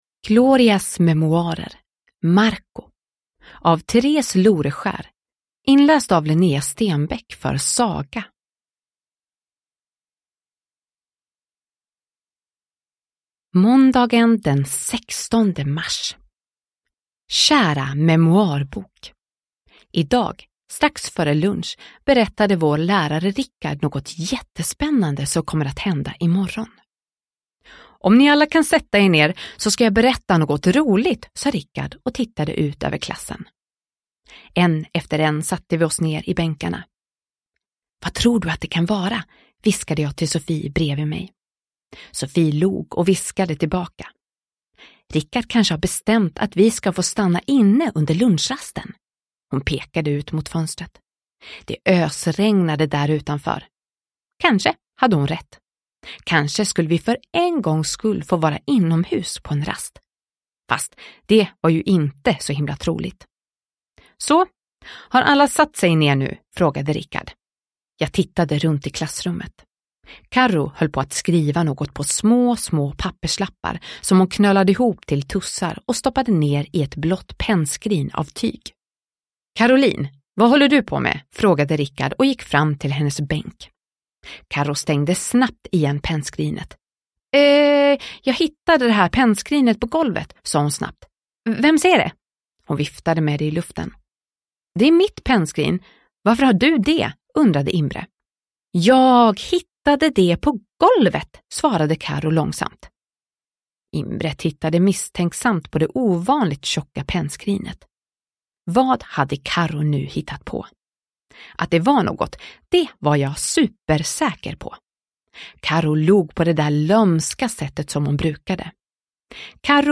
Glorias memoarer: Marco – Ljudbok – Laddas ner